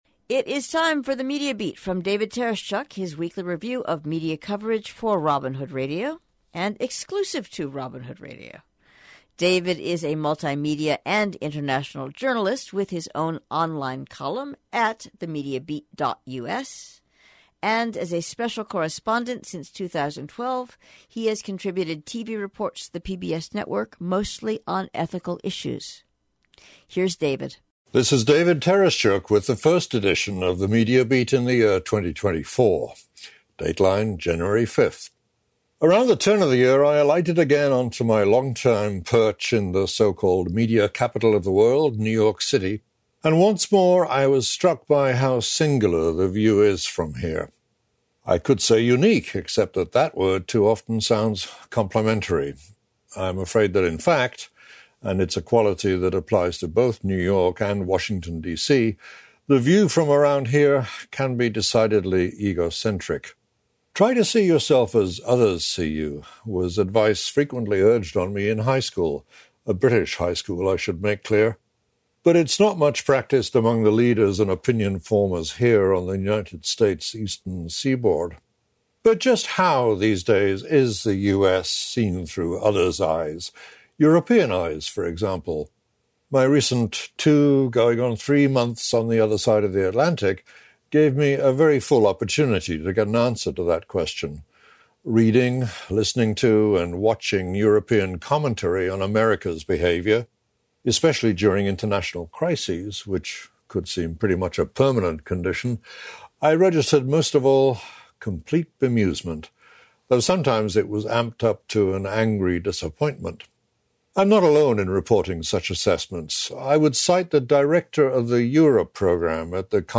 The audio version of THE MEDIA BEAT appears every week exclusively on Robin Hood Radio – first on Friday morning and rebroadcast over the weekend.